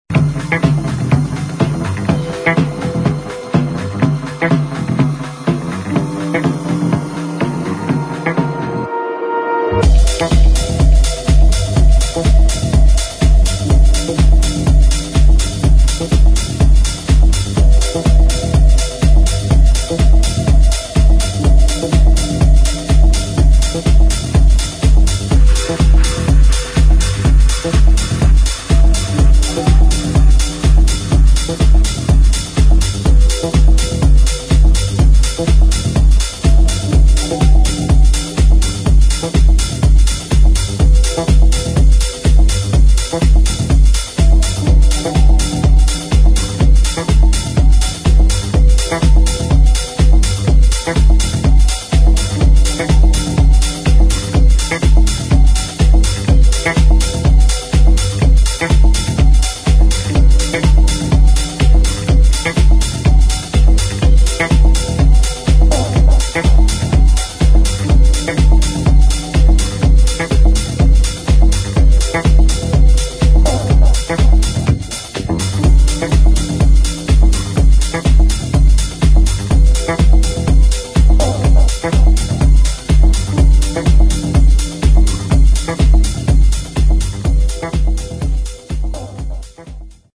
[ DEEP HOUSE / TECHNO ]